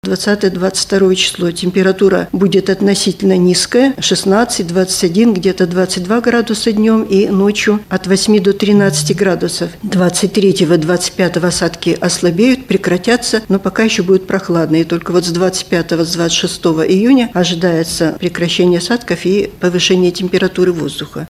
на пресс-конференции в агентстве «ТАСС».